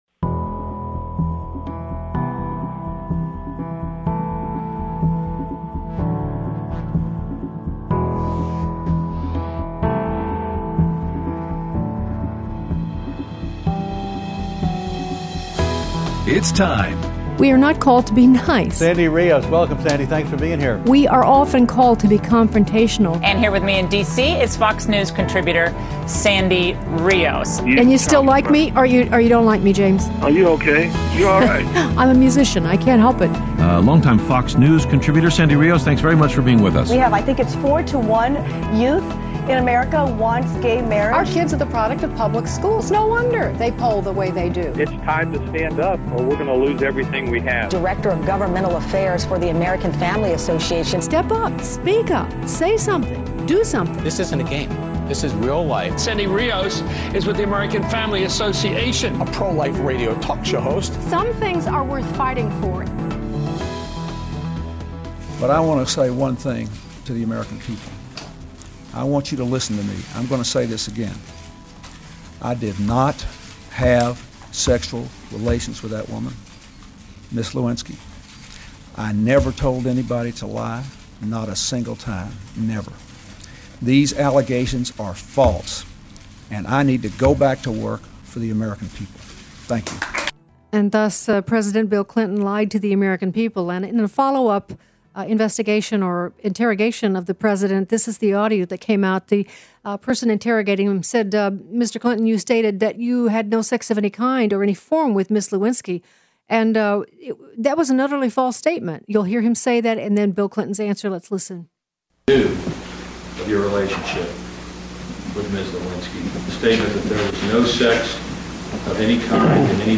Interview with Juanita Broaddrick
Aired Wednesday 10/26/16 on AFR Talk 7:05AM - 8:00AM CST